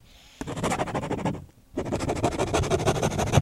Ballpoint Pen On Thick Paper, Loop